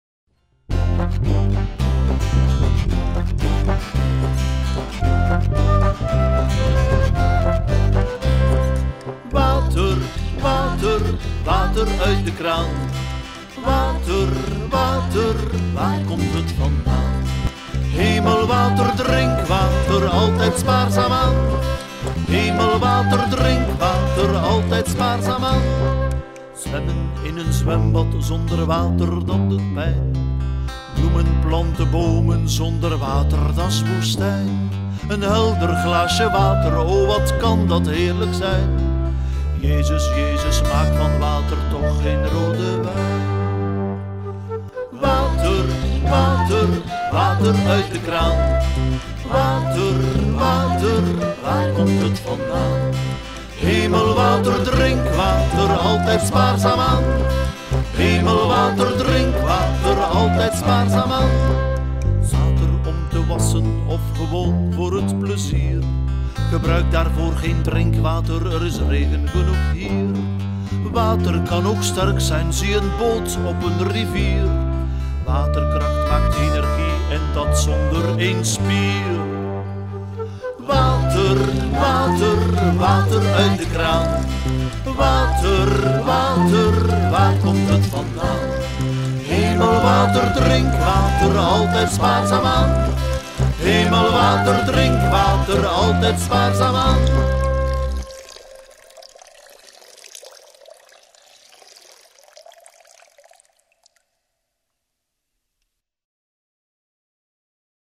Zingen jullie mee met dit vrolijke lied over water?